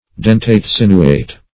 Search Result for " dentate-sinuate" : The Collaborative International Dictionary of English v.0.48: Dentate-sinuate \Den"tate-sin"u*ate\, a. (Bot.) Having a form intermediate between dentate and sinuate.